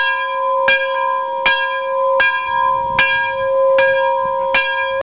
CampanaTreno.wav